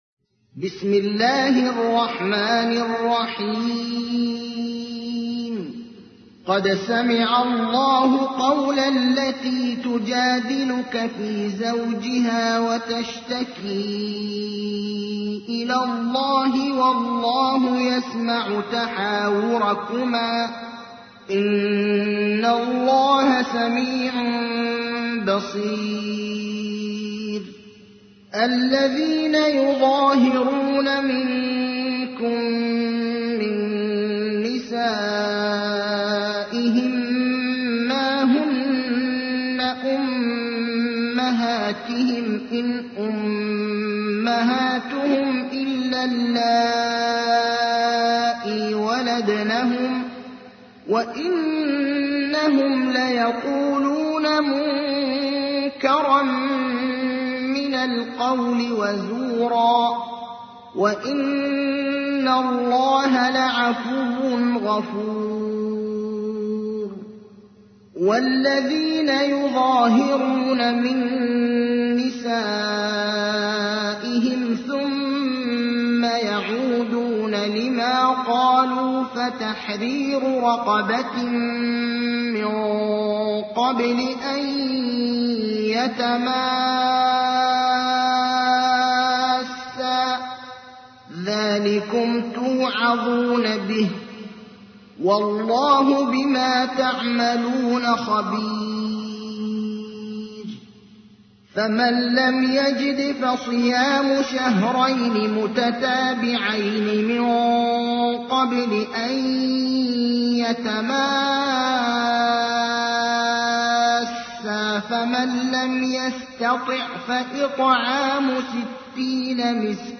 تحميل : 58. سورة المجادلة / القارئ ابراهيم الأخضر / القرآن الكريم / موقع يا حسين